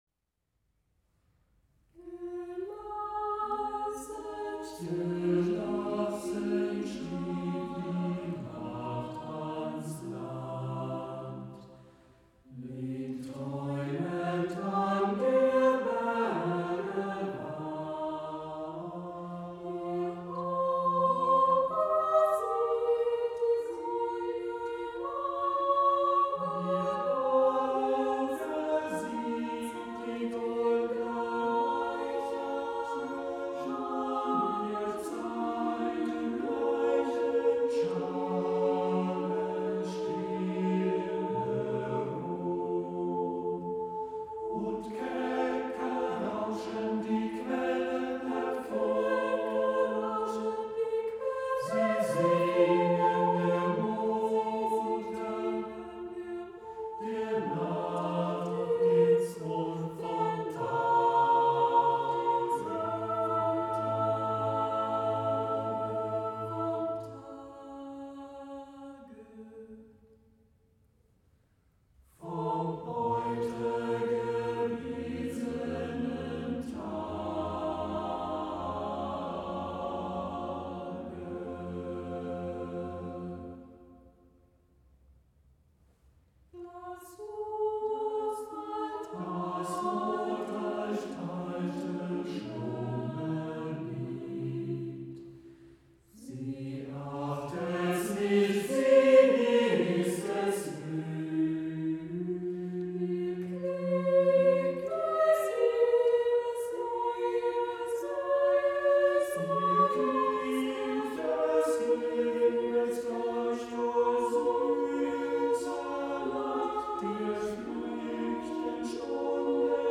zu den herausragenden a cappella Chorbüchern des 20.
für gemischten Chor